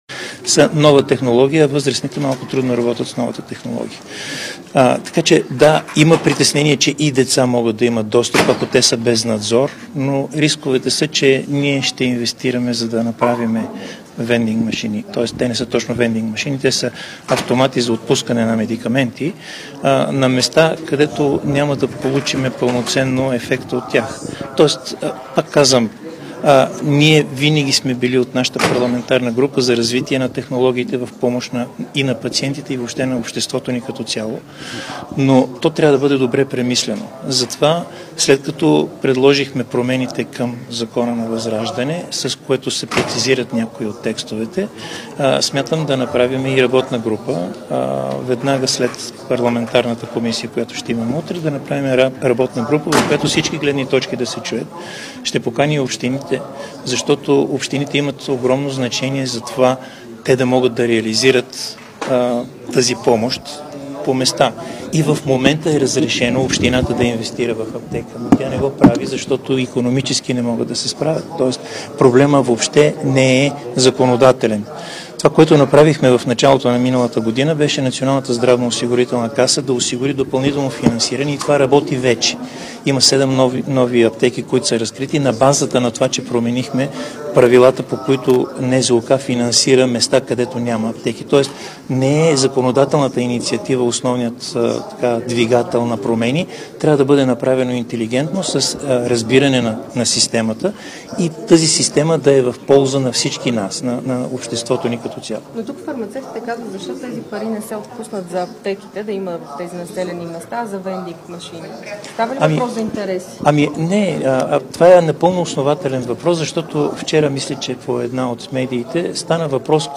10.30 - Брифинг на Андрей Чорбанов от ИТН за вендинг машините за лекарства.  - директно от мястото на събитието (Народното събрание)
Директно от мястото на събитието